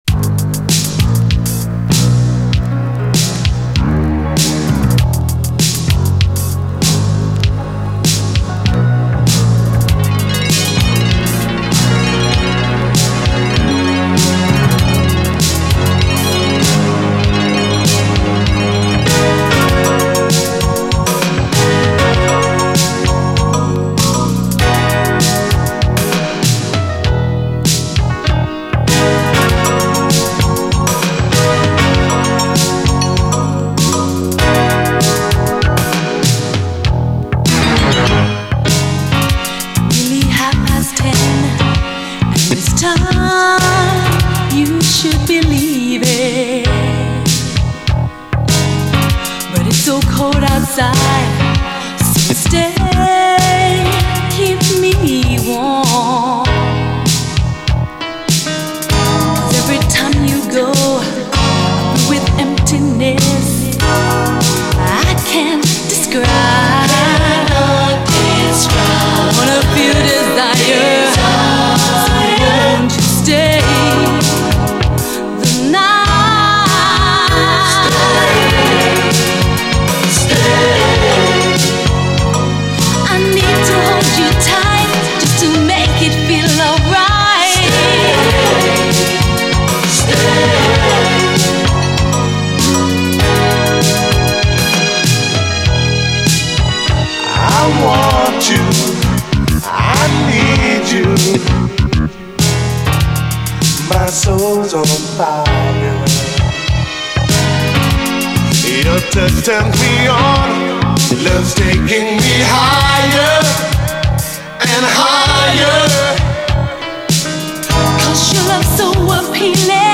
SOUL, 70's～ SOUL
非常にやるせない88年メロウUKストリート・ソウル！